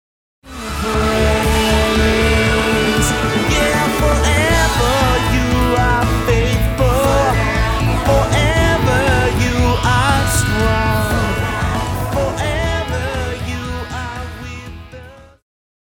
Vocal - female,Vocal - male
Band
POP,Christian Music
Instrumental
Solo with accompaniment